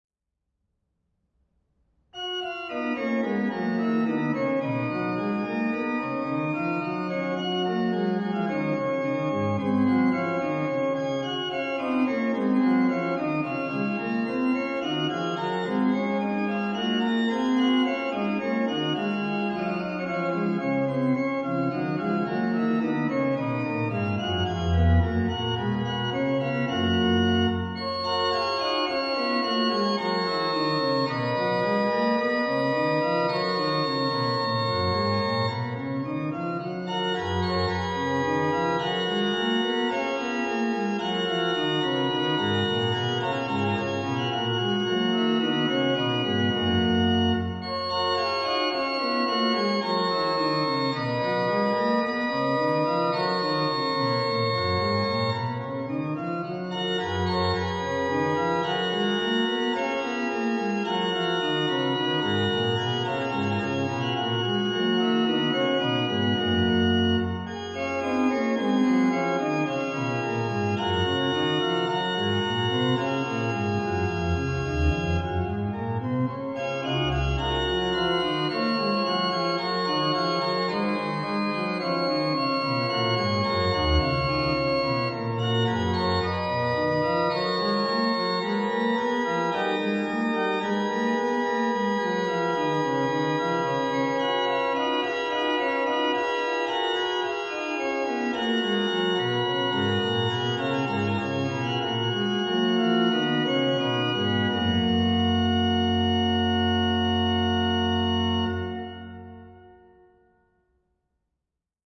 German Baroque
" this chorale prelude is written for manuals only.
Baroque